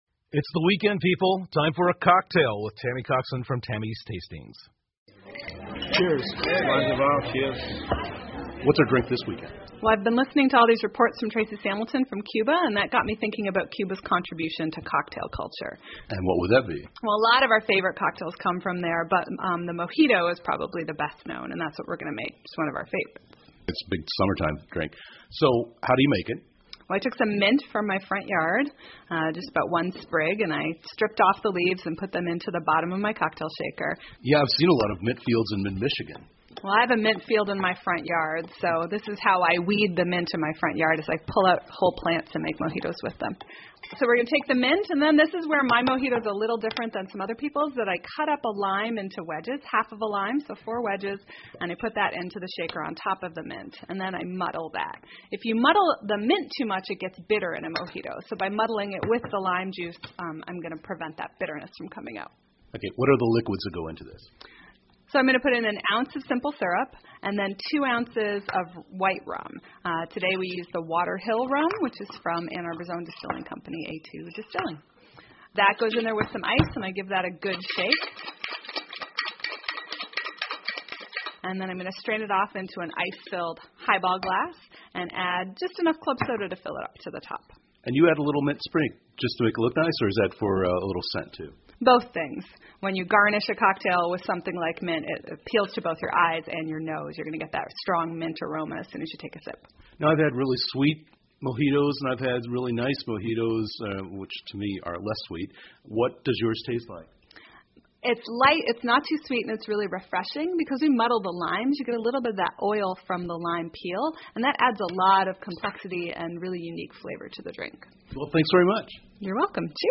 密歇根新闻广播 教你做密歇根风情的鸡尾酒Mojito 听力文件下载—在线英语听力室